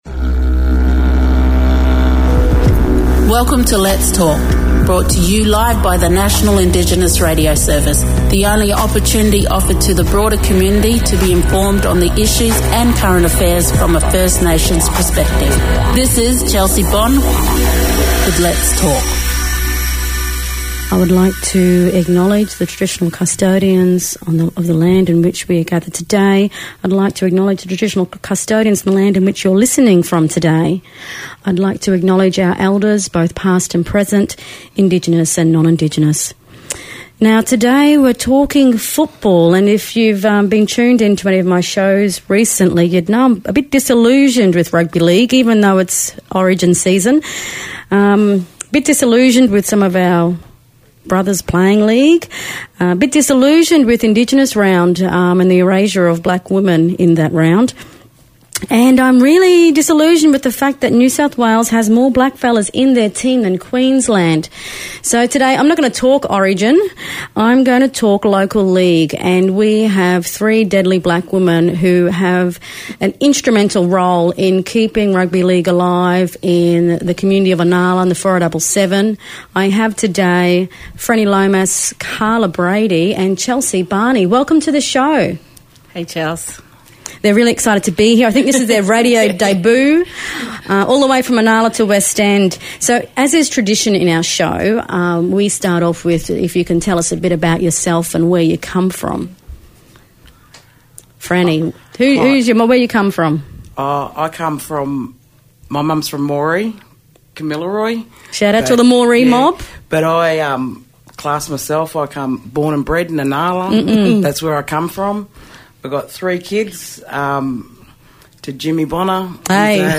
is talking with some strong black women who have been keeping footy alive in Inala (and some even play the game).